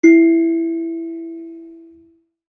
kalimba1_circleskin-E3-ff.wav